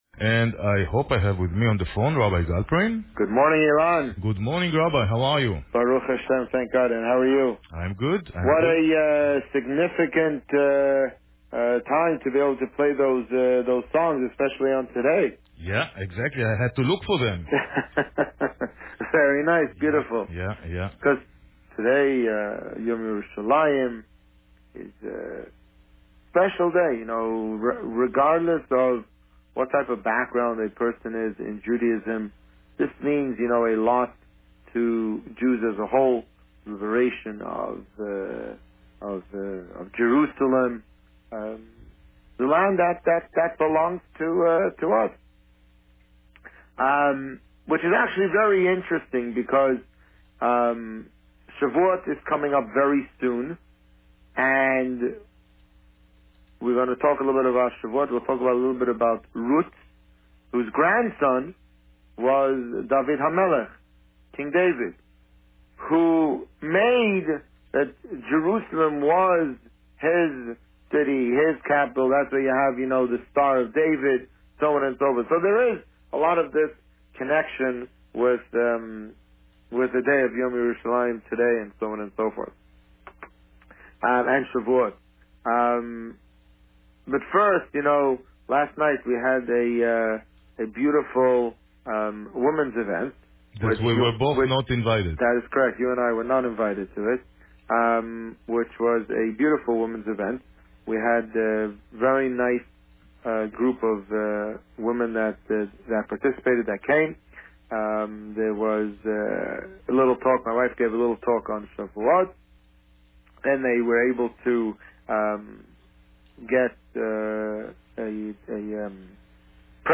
The Rabbi on Radio Shavuot and Jerusalem Day Published: 25 May 2017 | Written by Administrator On May 25, 2017, the Rabbi spoke about Shavuot and Jerusalem Day.